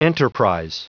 Prononciation du mot enterprise en anglais (fichier audio)
Prononciation du mot : enterprise